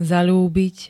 zaľúbiť [-ľú-, -lí-] dk
Zvukové nahrávky niektorých slov
mpaz-zalubit.ogg